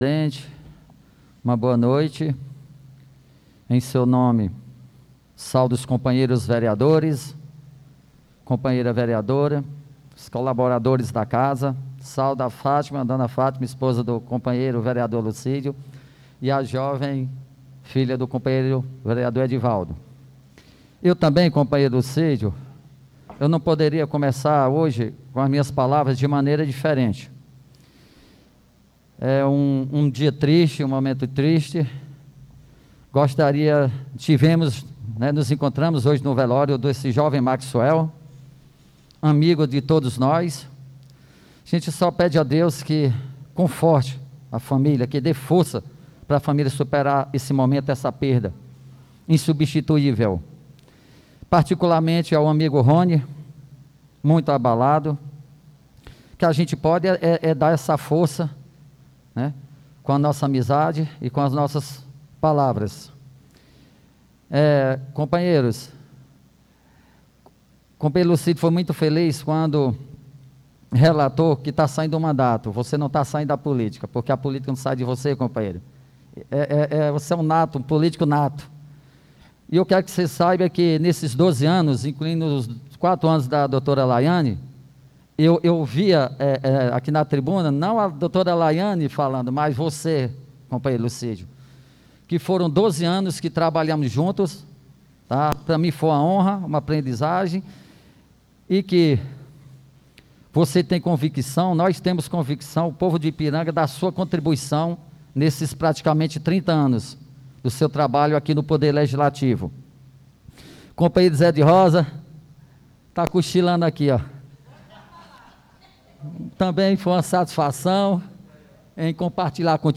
Pronunciamento Ver Paulo Cortez